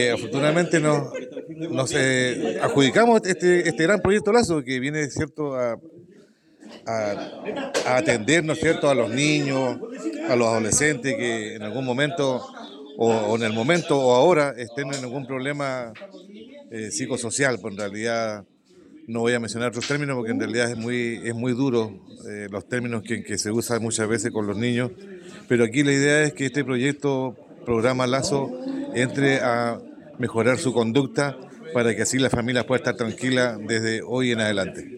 En tanto, el alcalde Baltazar Elgueta no ocultó su satisfacción por la iniciativa que es dirigida desde el nivel central: